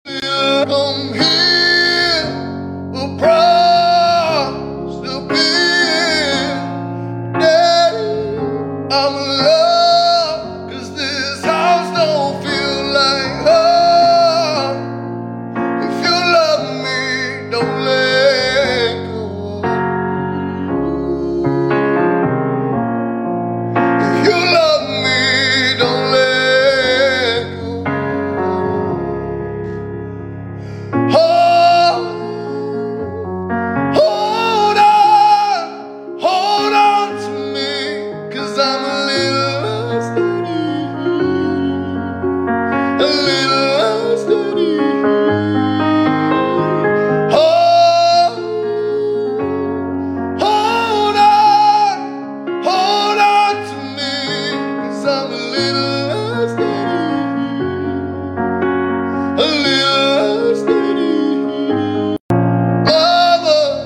😔😔 thats the sound of a fella that didnt know he had that voice until his soul was crushed..... i hope not but yeah.. lad can sinnggg gets you right in the feels 😔 Why Did I Just Watch Sound Effects Free Download.